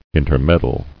[in·ter·med·dle]